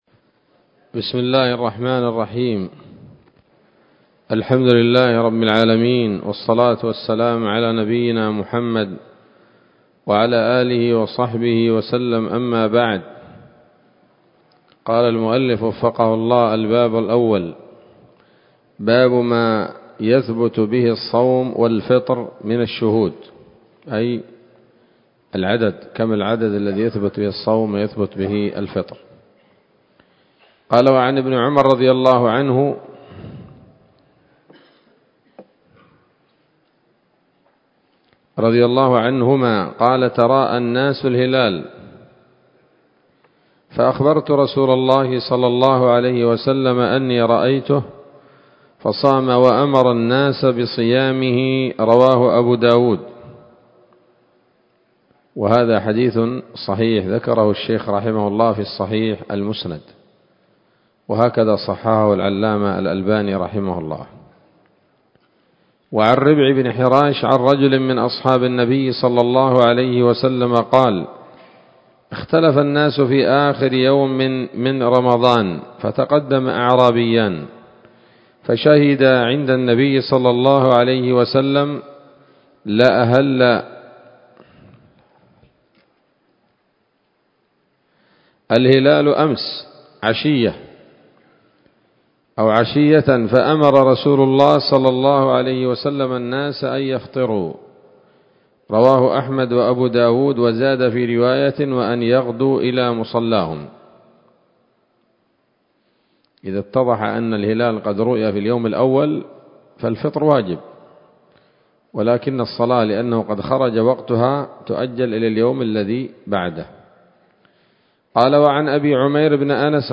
الدرس الثاني من كتاب الصيام من نثر الأزهار في ترتيب وتهذيب واختصار نيل الأوطار